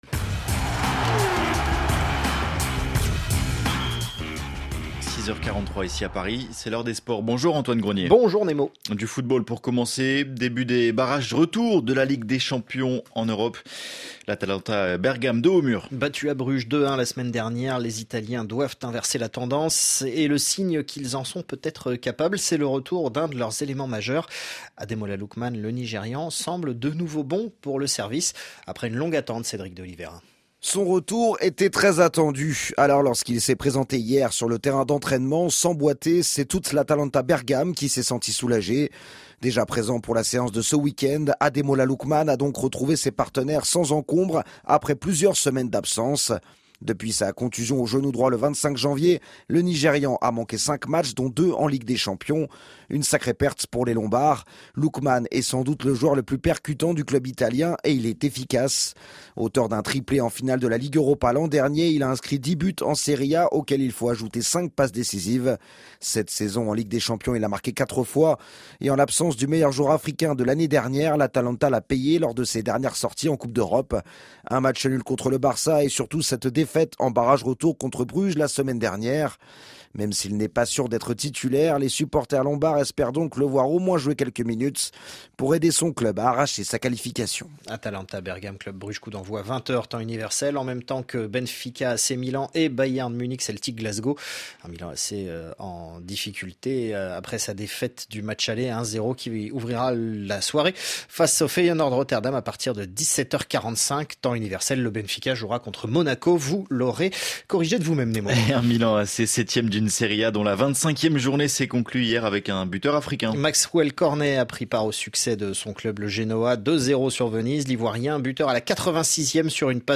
En 4 minutes chaque jour, toute l'actualité sportive du continent africain et des sportifs africains dans le monde. Présenté par le Service des Sports de RFI.